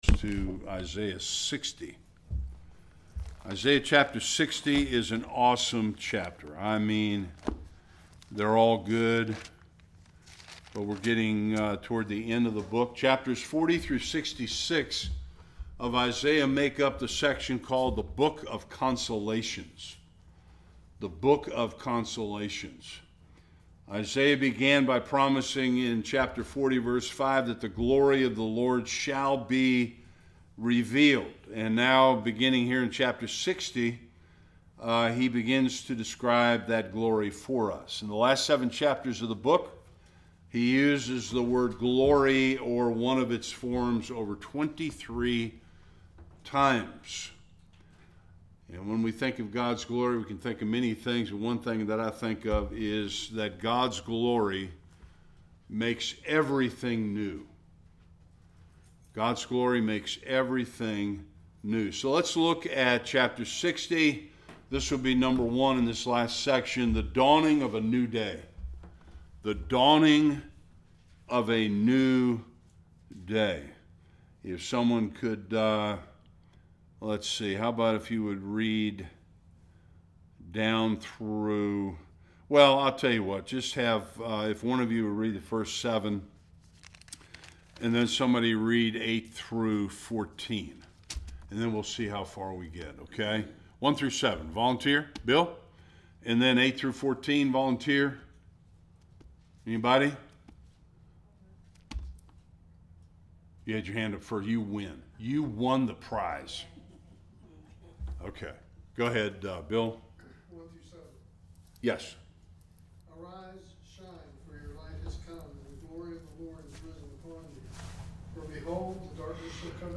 1-7 Service Type: Bible Study Follow God’s glory through the pages of Scripture.